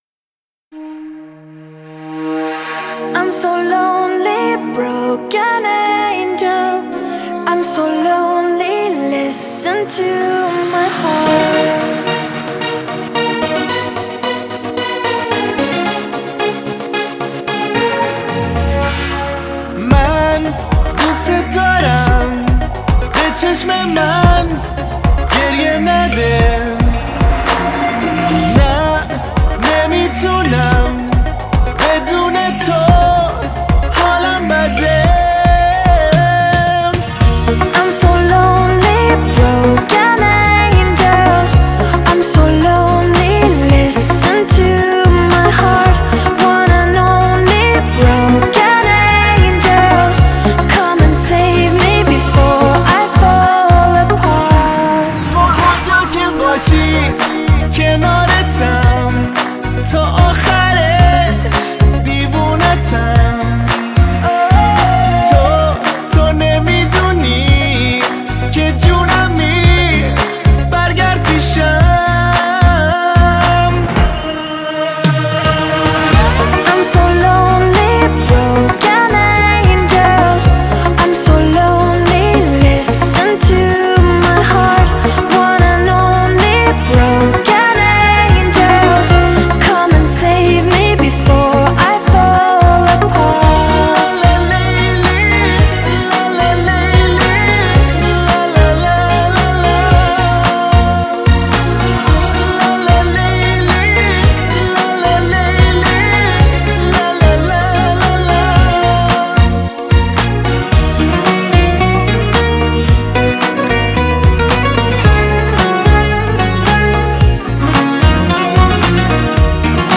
это популярная песня в жанре поп и евродэнс